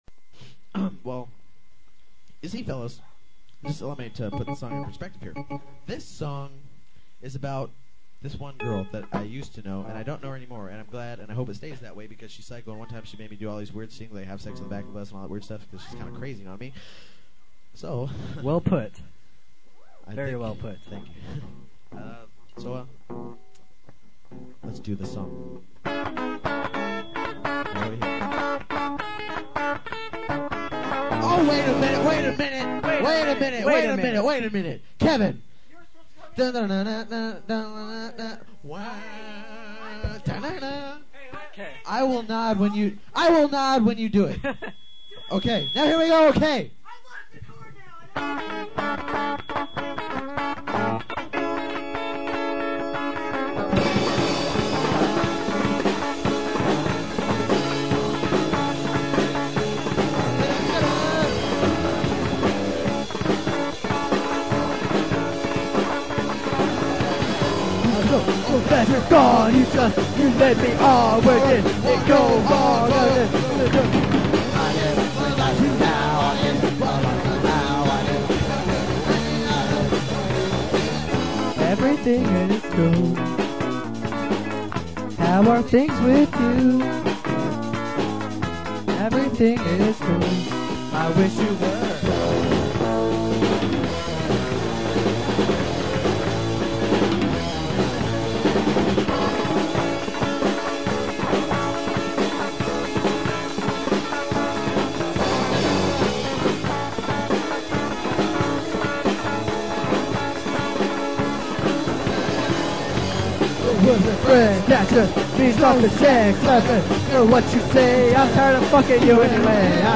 Guitar
Drums/Vocals
Bass/Vocals
Keyboard